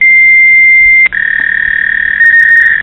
modem.mp3